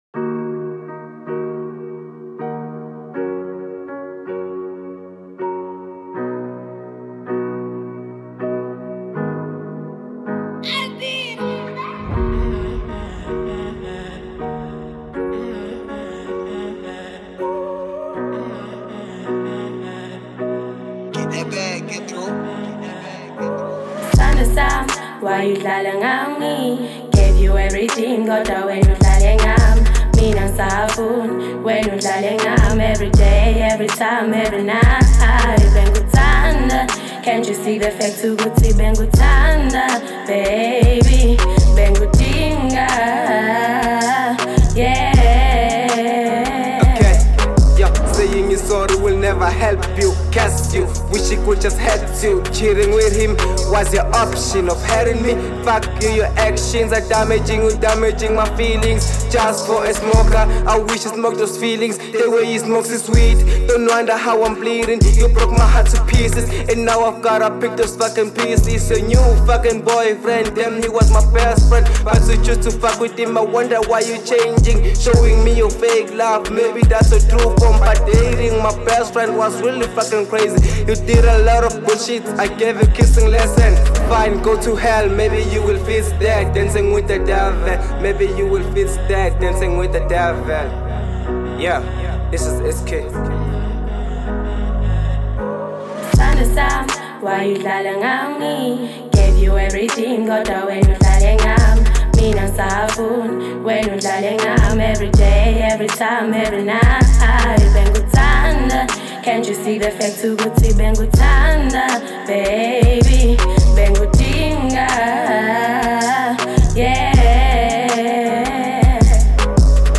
03:36 Genre : Hip Hop Size